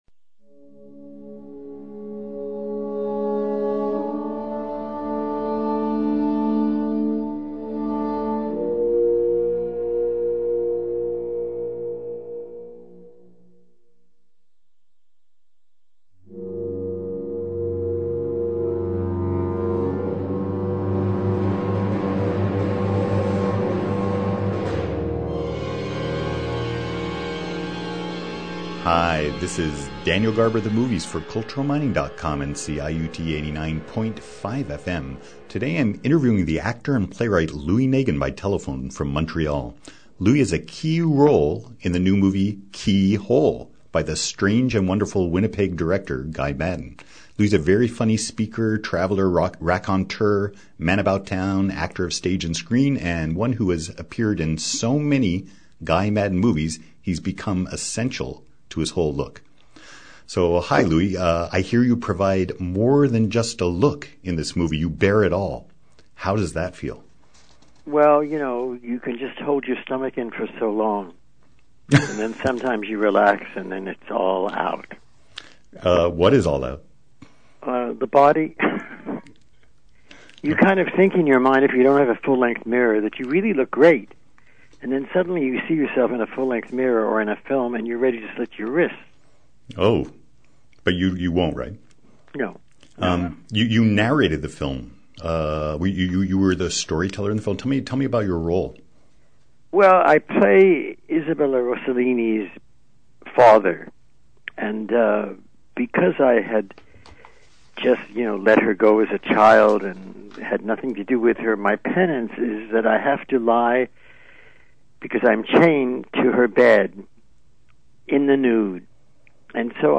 Interview: Louis Negin on his role in Guy Maddin’s upcoming film Keyhole.